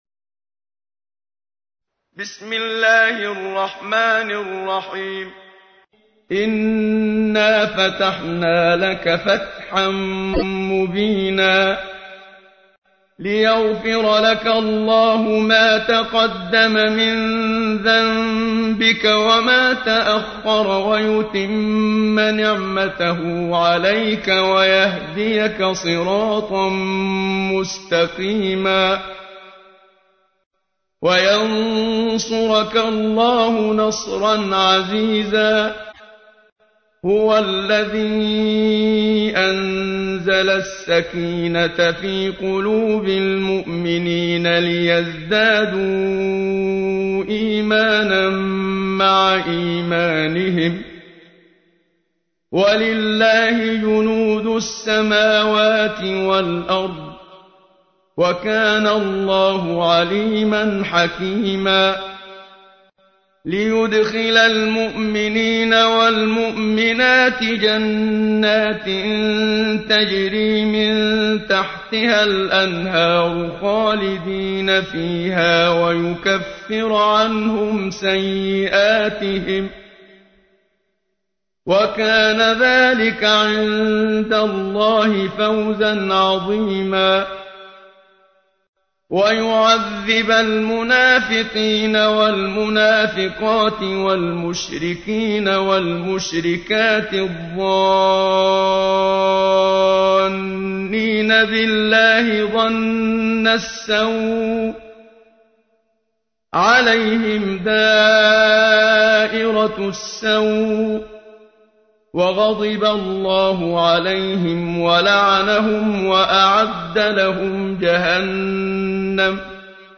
تحميل : 48. سورة الفتح / القارئ محمد صديق المنشاوي / القرآن الكريم / موقع يا حسين